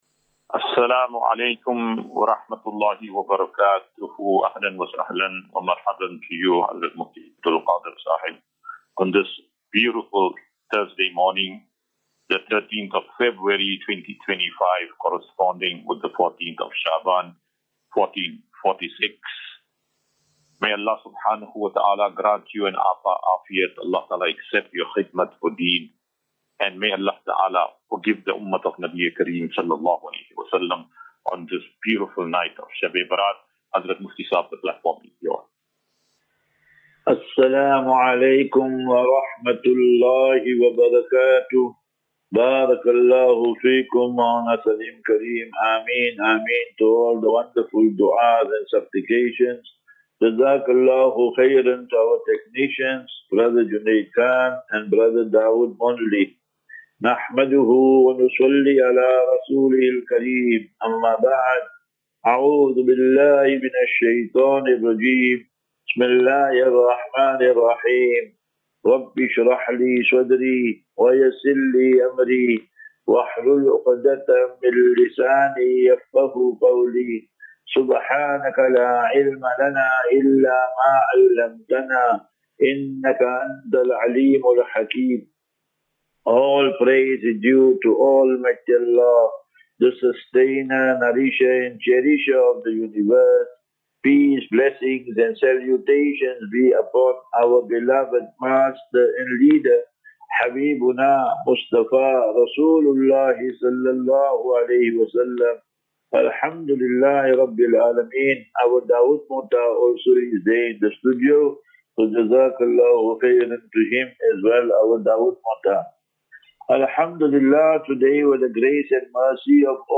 General Naseeha.